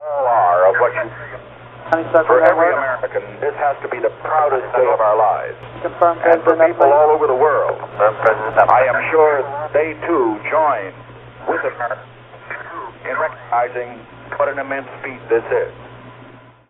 A ‘career highlight’ occurs during the Apollo 11 EVA: